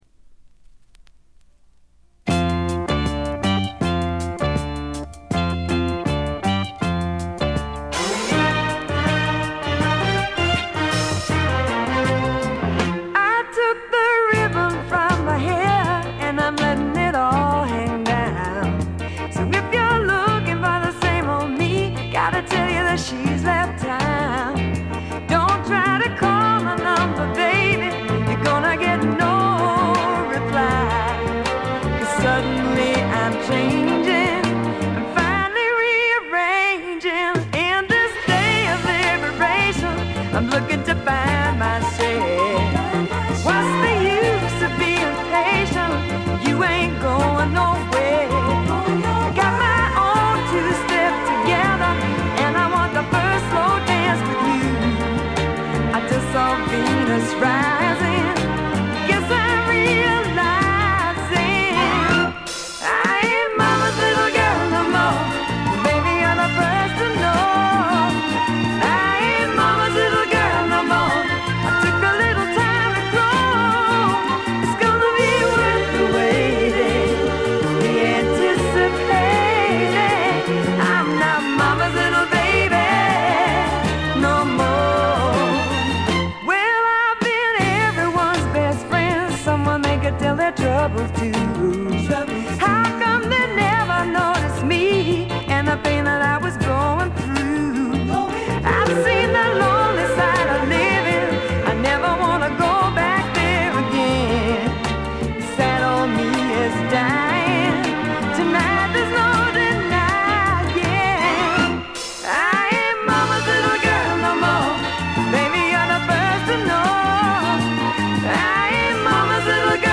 大きなノイズはありません。
フリー・ソウル系のレアグルーヴものとしても高人気の一枚です。
試聴曲は現品からの取り込み音源です。